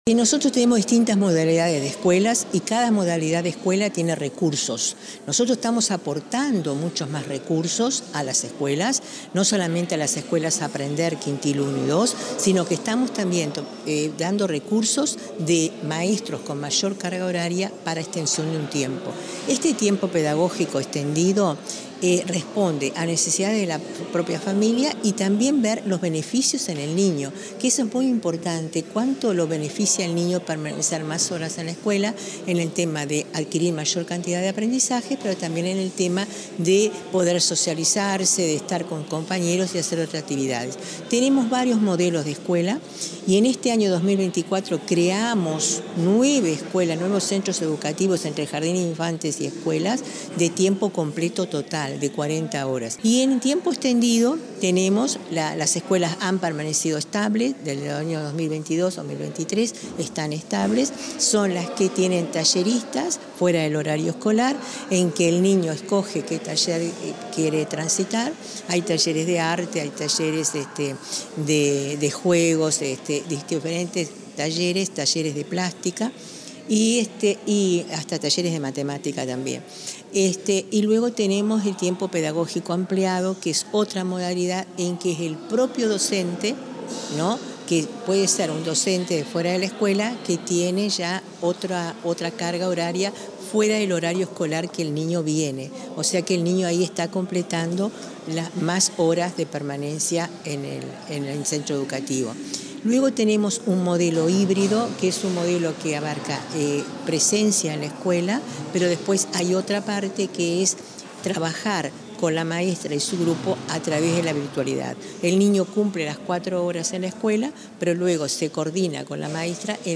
Entrevista a la directora general de Educación Inicial y Primaria, Olga de las Heras